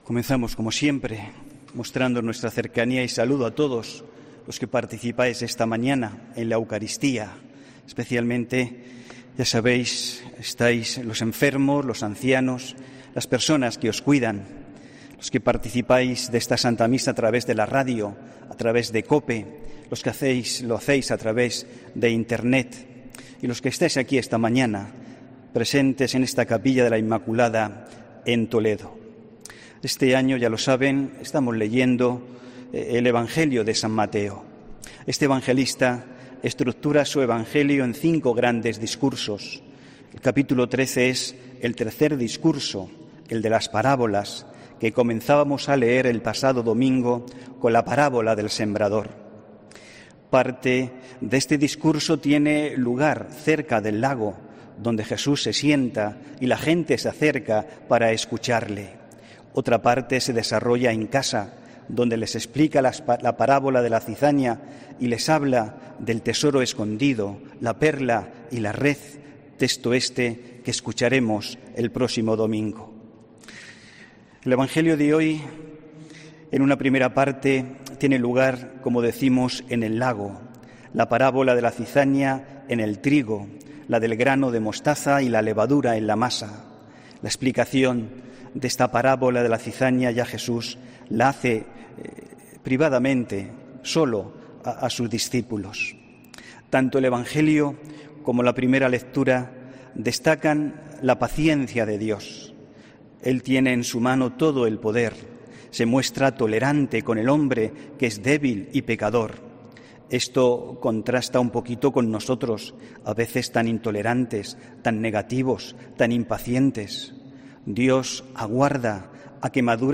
HOMILÍA 19 JULIO 2020